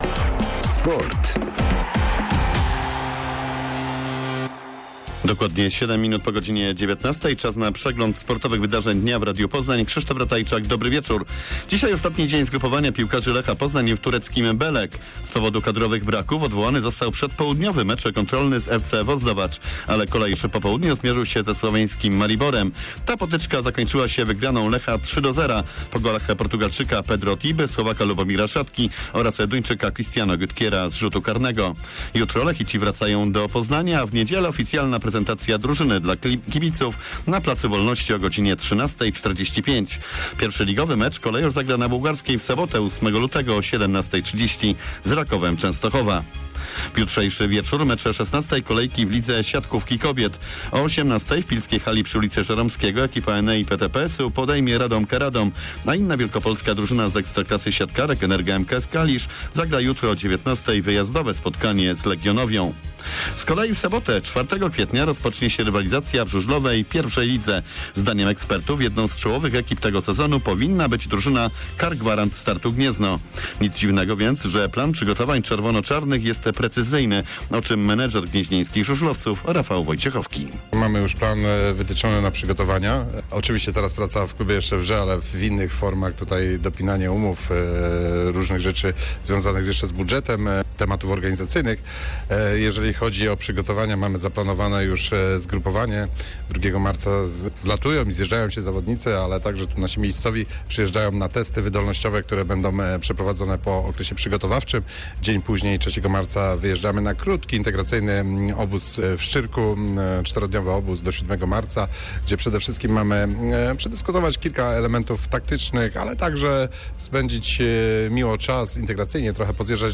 28.01. SERWIS SPORTOWY GODZ. 19:05